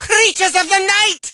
mortis_ulti_vo_01.ogg